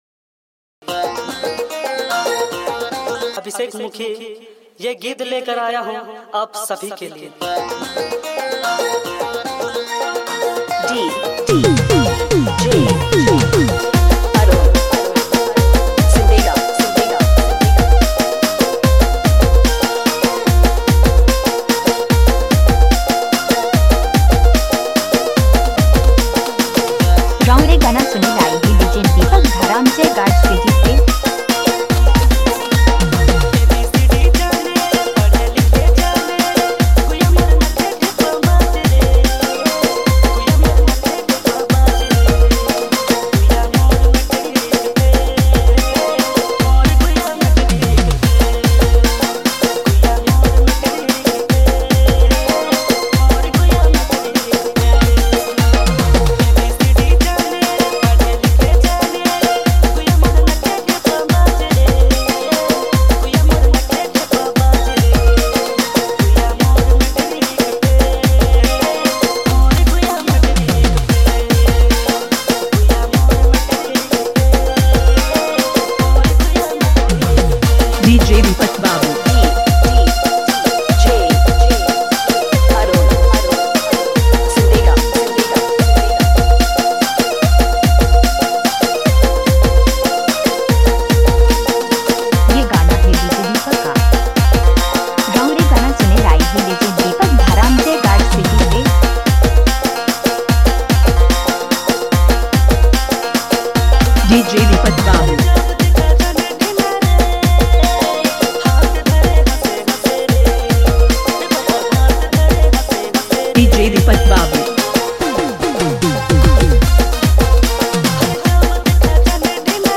vibrant rhythms of Nagpuri music